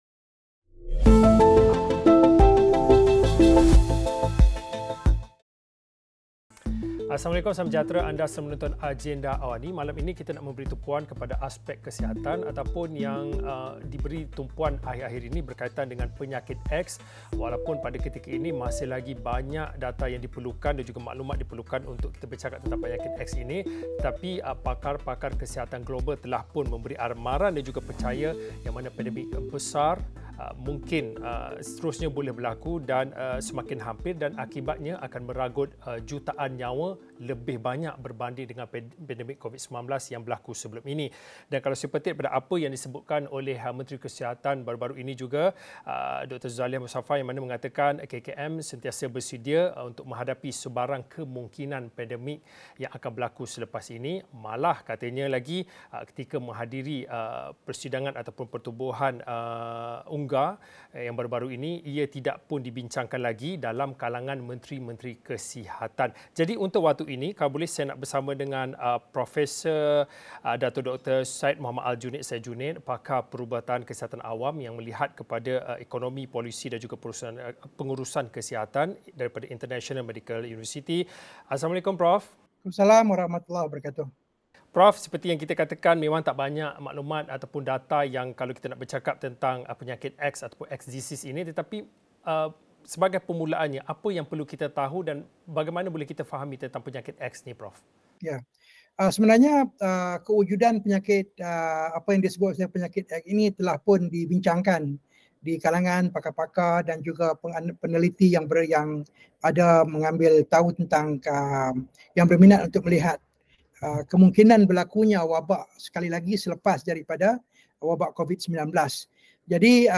Diskusi 8.30 malam.